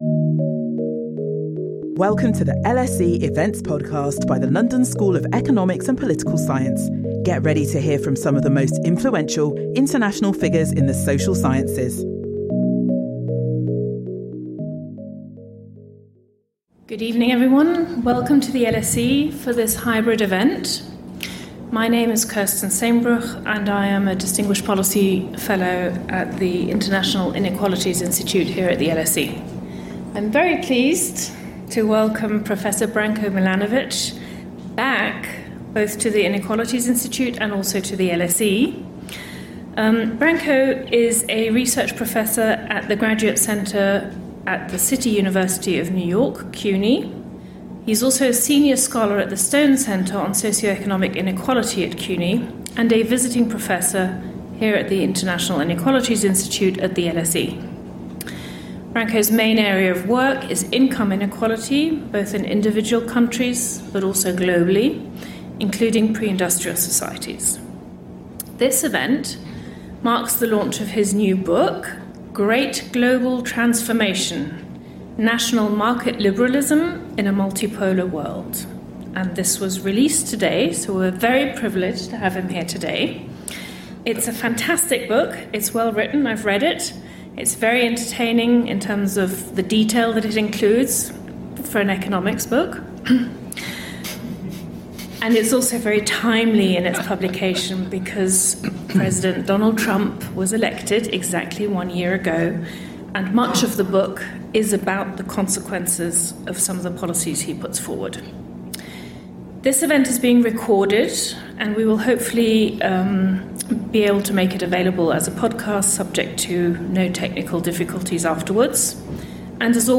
Join us for this talk by Branko Milanovic about his new book, The Great Global Transformation: National Market Liberalism in a Multipolar World.